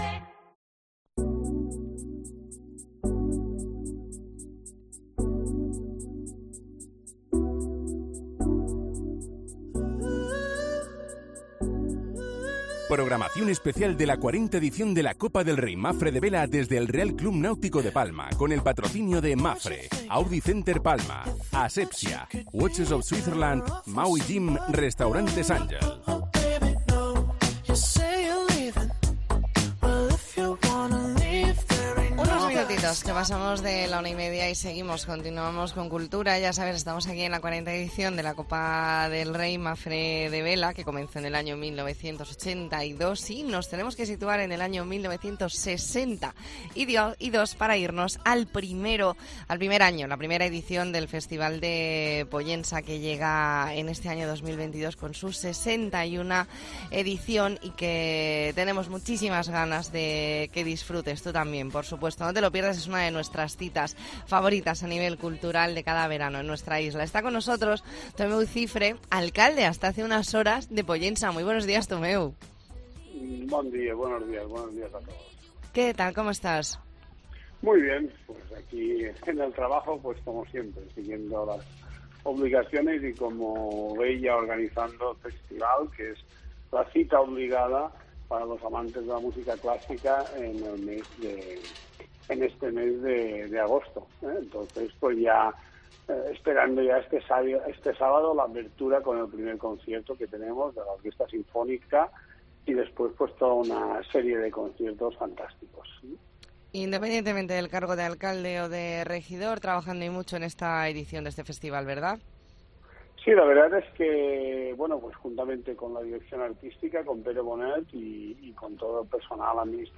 Entrevista en La Mañana en COPE Más Mallorca, jueves 4 de agosto de 2022.